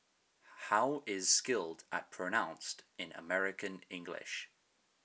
British_English_Speech_Data_by_Mobile_Phone